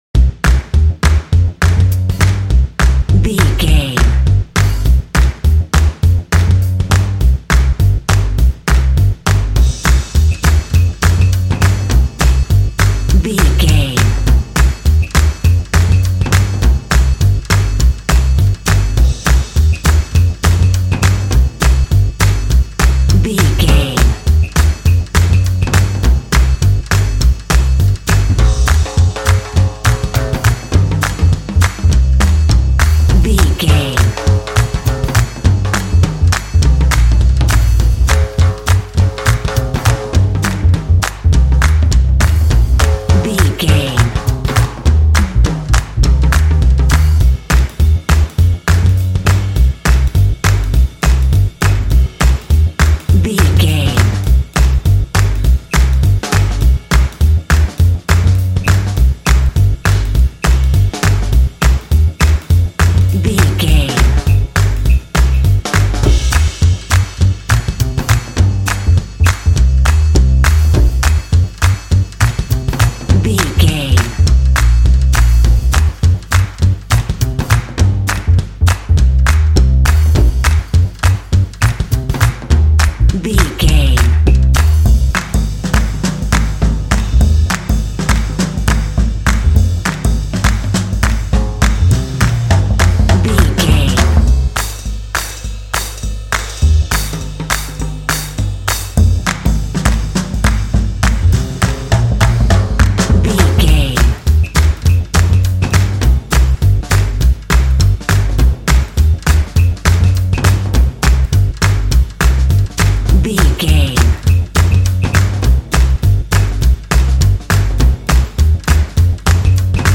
Uplifting
Aeolian/Minor
driving
energetic
bouncy
joyful
cheerful/happy
double bass
percussion
drums
big band